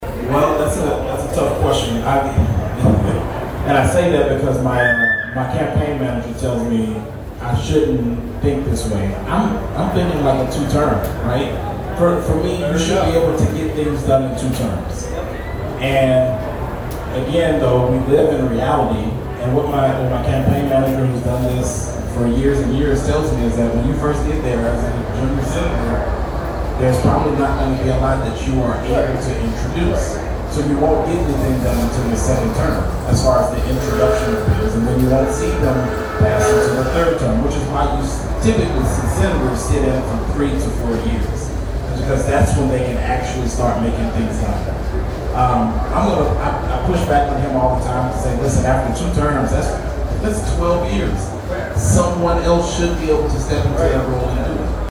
in the town hall the Democrat Party hosted on Saturday